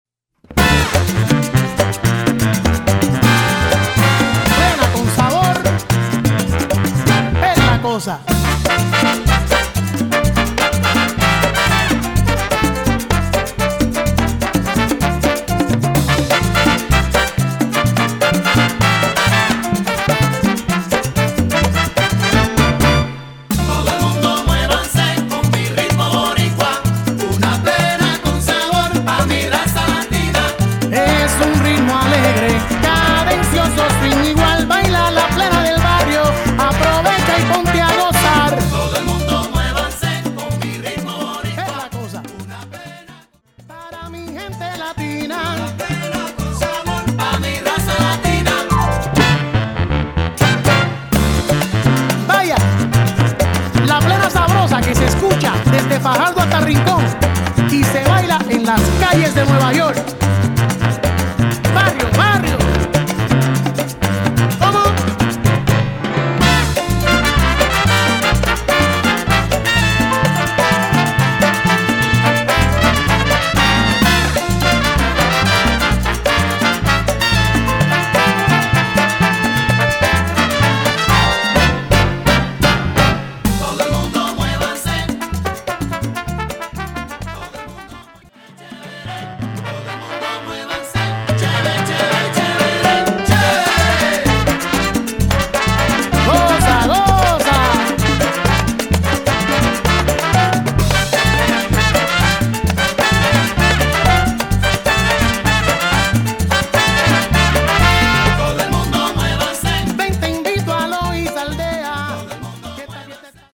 Category: salsa
Style: plena
Solos: vocal, trombone